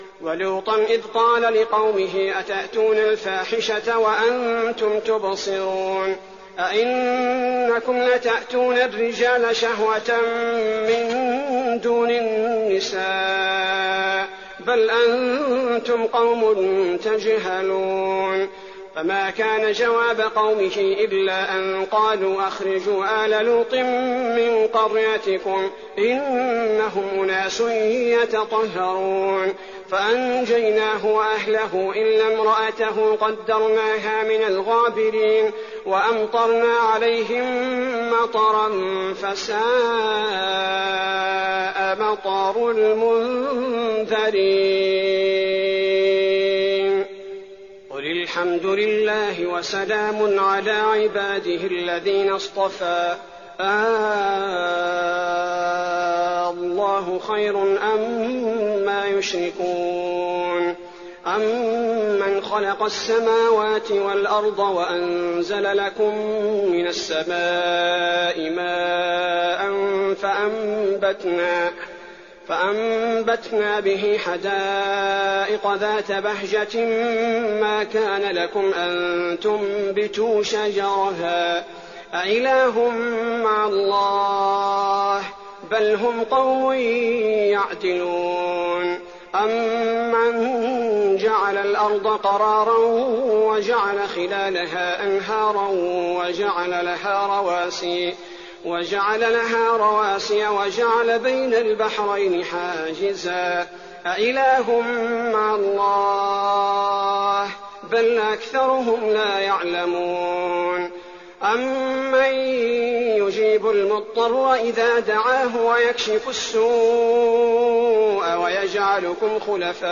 تراويح الليلة التاسعة عشر رمضان 1419هـ من سورتي النمل (54-93) و القصص(1-28) Taraweeh 19th night Ramadan 1419H from Surah An-Naml and Al-Qasas > تراويح الحرم النبوي عام 1419 🕌 > التراويح - تلاوات الحرمين